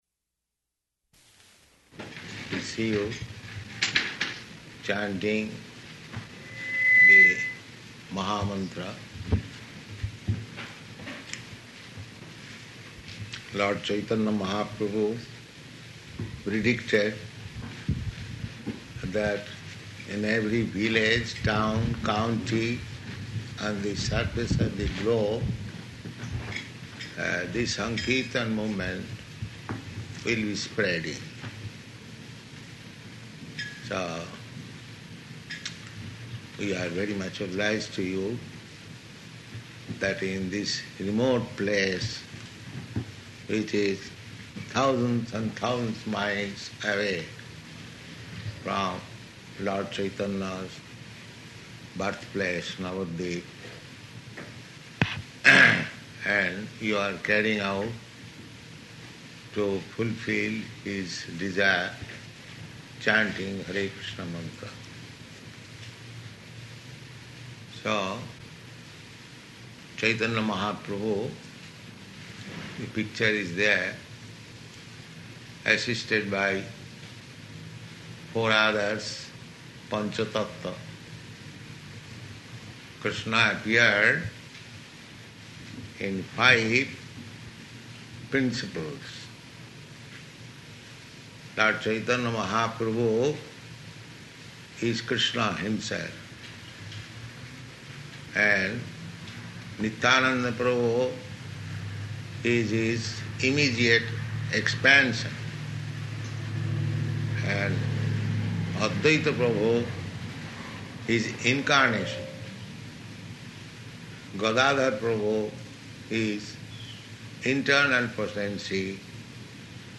Arrival Lecture
Arrival Lecture --:-- --:-- Type: Lectures and Addresses Dated: July 29th 1971 Location: Gainesville Audio file: 710729AR-GAINESVILLE.mp3 Prabhupāda: ...see you chanting the mahā-mantra.